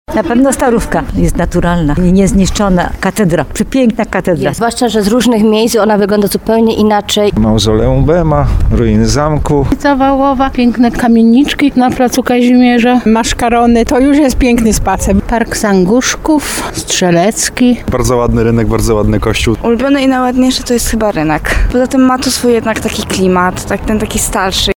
7tarnow_sonda.mp3